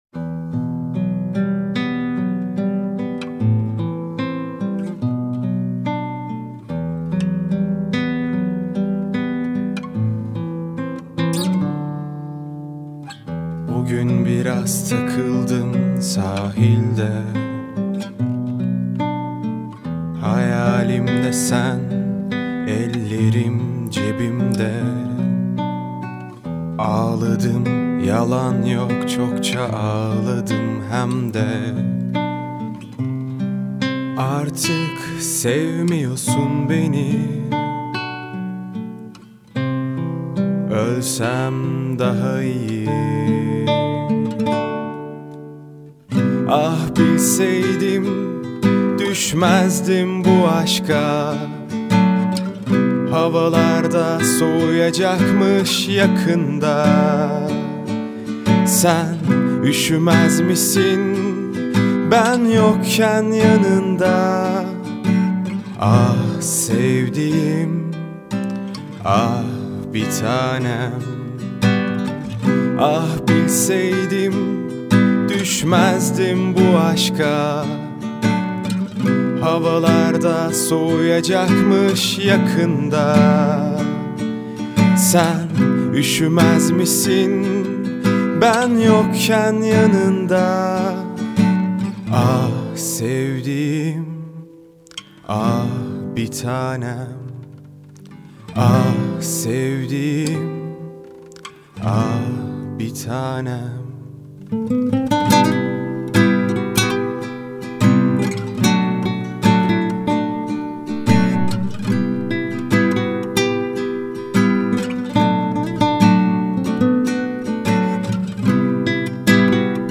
duygusal hüzünlü rahatlatıcı şarkı.